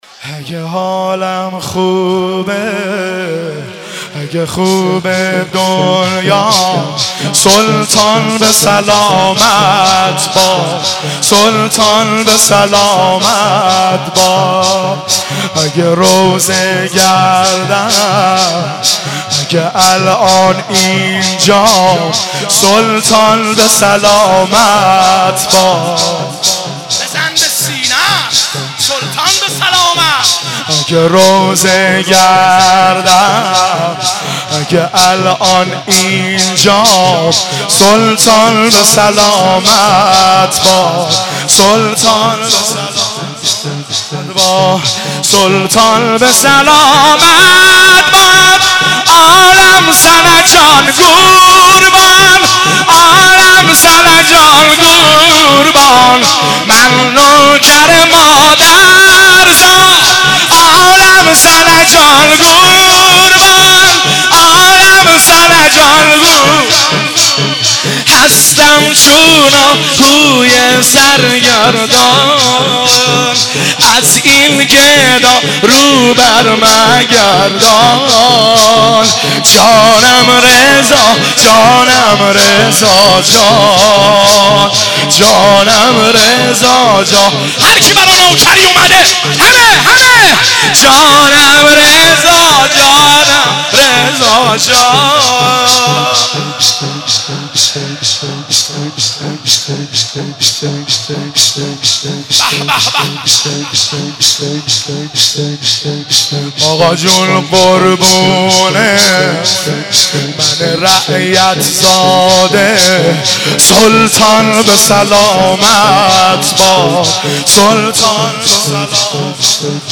شب شهادت امام رضا(ع) 1396
مشهد مقدس
هیئت خادم الرضا قم